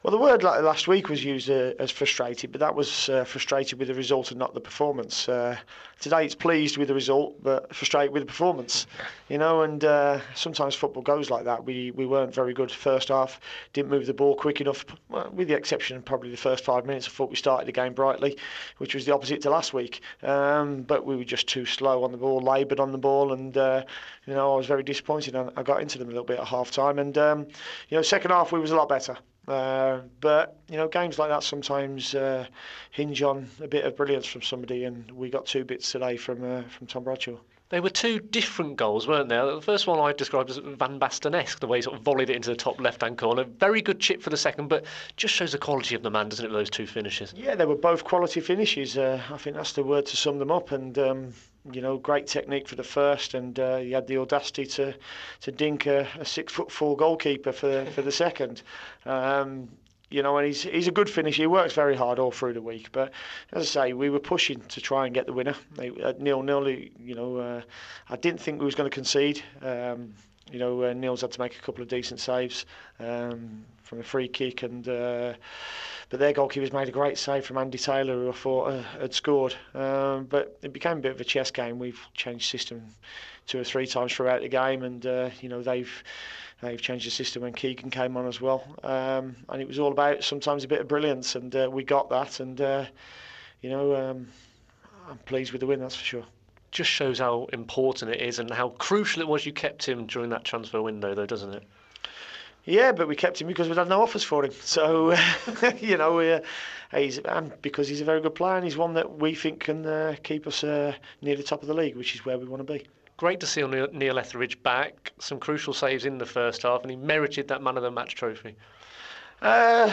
talks to Dean Smith post-match at the Bank's Stadium.